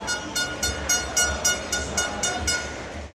Bocina en el Estadio Pascual Guerrero